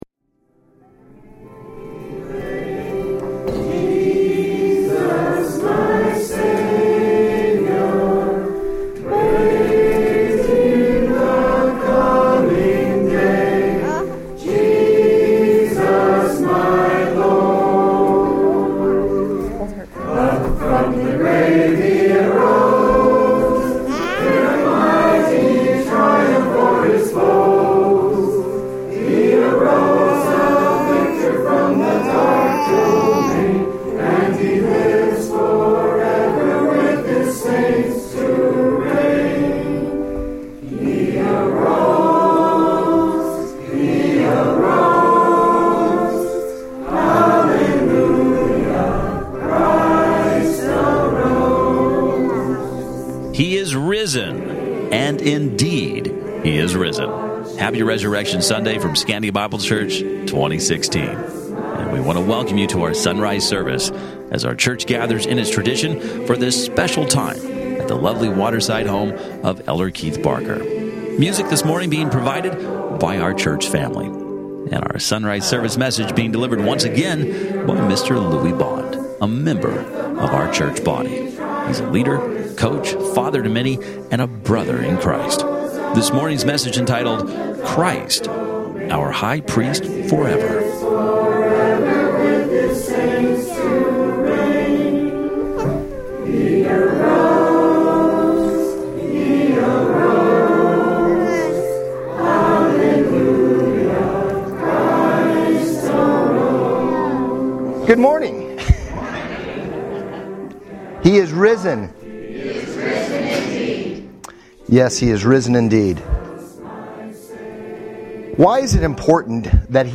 Date: 03/27/2016, Easter Sunday